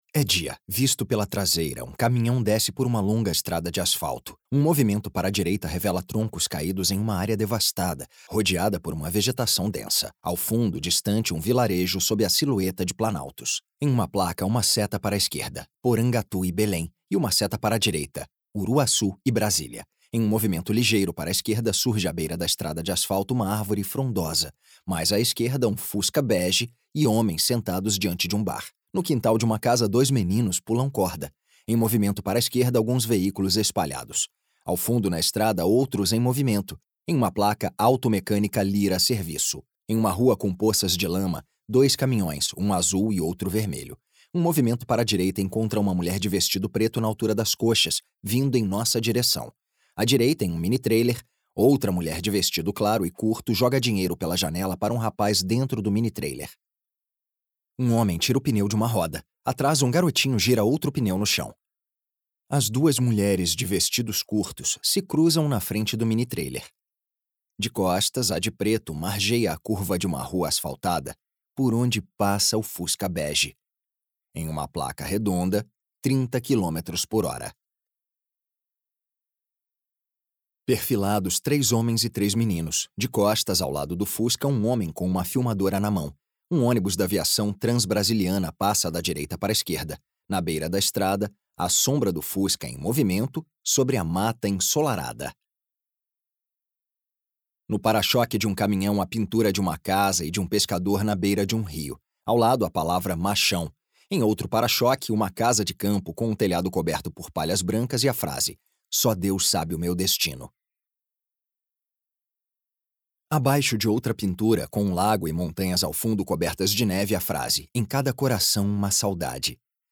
A câmera de Jorge Bodanzky durante a ditadura brasileira (1964-1985) - Audiodescrição | Estação 06 - Instituto Moreira Salles